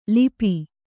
♪ lipi